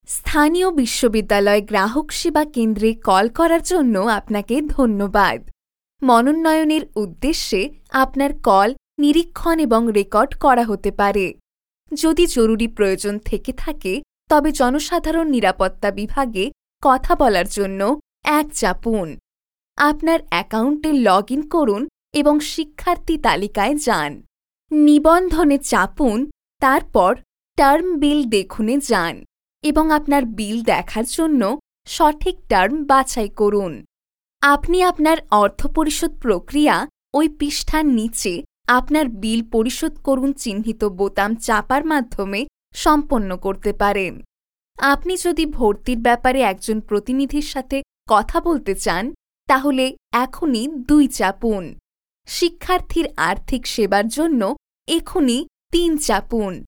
Professional Bengali Voice Talents